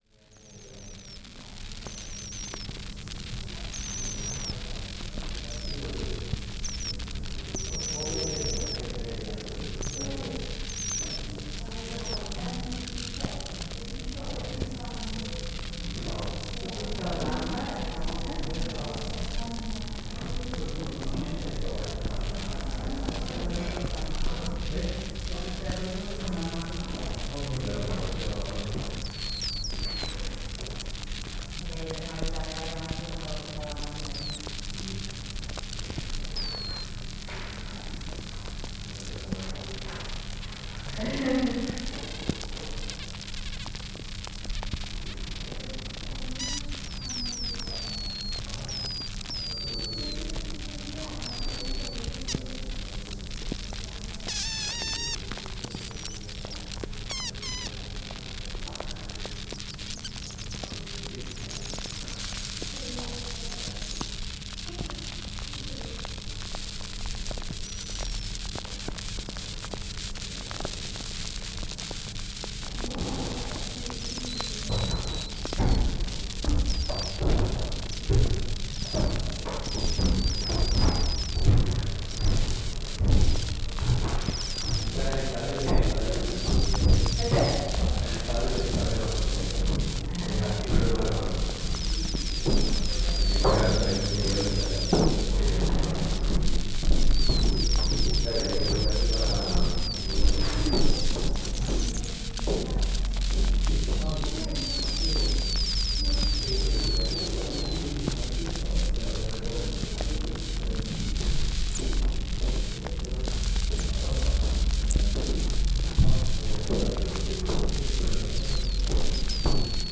in january, 2012 i visited the cantillon brewery in brussels, where they practice traditional spontaneous fermentation brewing. this means that no yeast or other fermenting agents are added to the brews; everything needed is present in the atmosphere. the wooden building is an ecosystem unto itself, having developed the perfect balance and blend of natural yeasts living in the beams and floorboards; these brews could not be made anywhere else. and yet the brewery is constantly threatened by authorities unsatisfied with their wooden facilities, hygiene and lack of sterilization, all things without which they could not brew. while in the aging room we came across this barrel, fermenting and foaming and releasing its gas around a large cork hammered into the top. the ecosystem in full swing.